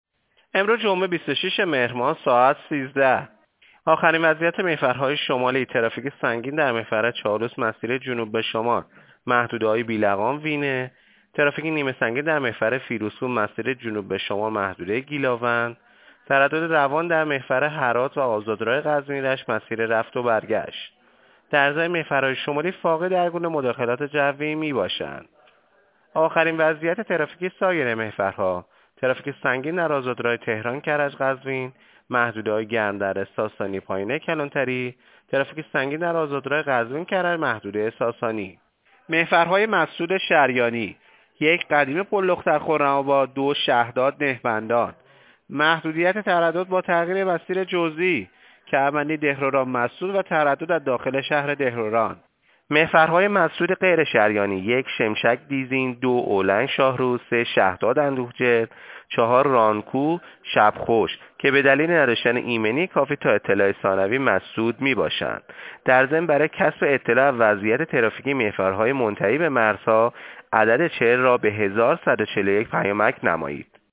گزارش رادیو اینترنتی پایگاه خبری وزارت راه و شهرسازی از آخرین وضعیت ترافیکی جاده‌های کشور تا ساعت ۱۳جمعه ۲۶ مهر/ ترافیک سنگین در محورهای چالوس، تهران-کرج-قزوین و قزوین-کرج/ ترافیک نیمه سنگین در محور فیروزکوه